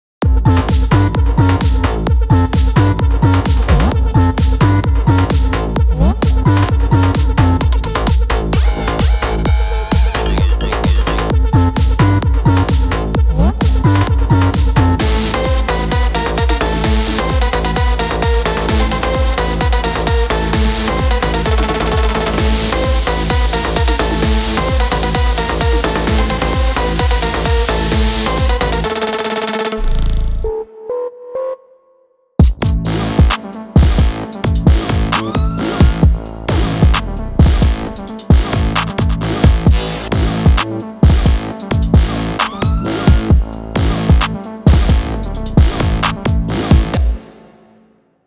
אהבתי את השיחוק הסטריאופוני!!